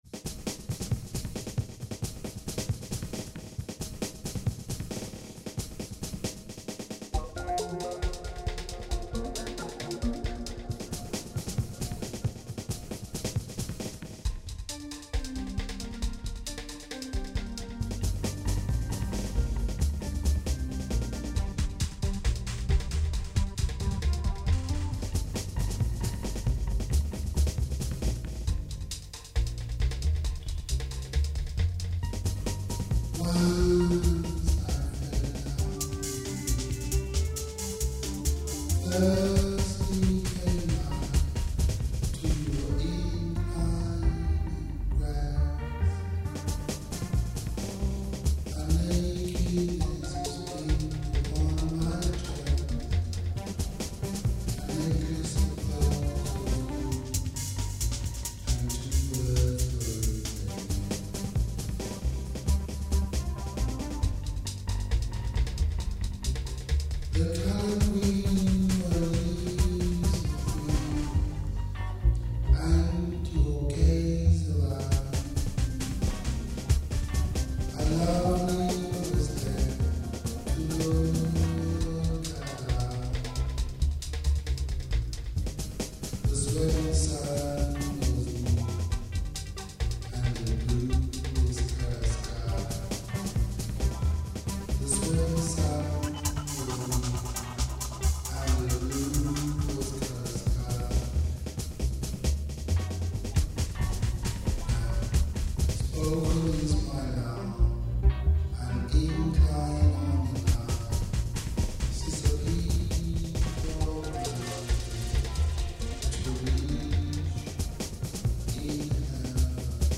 ....My duB-poetry specialist site....
hi=pop